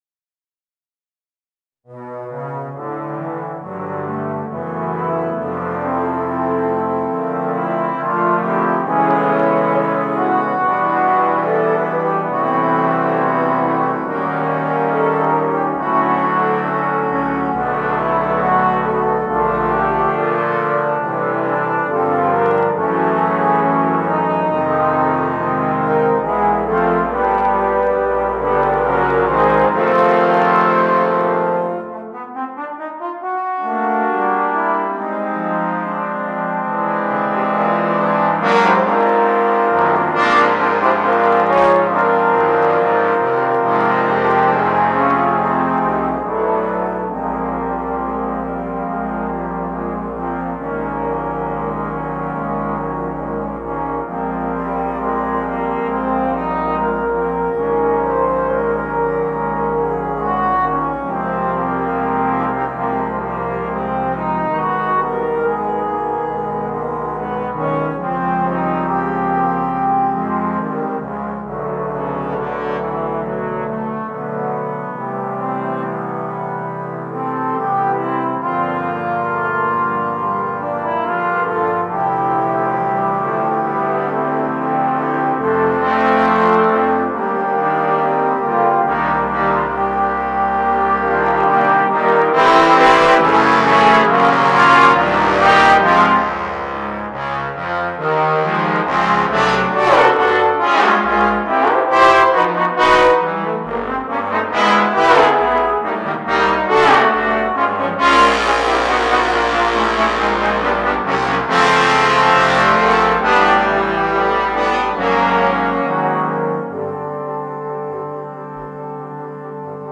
8 Trombones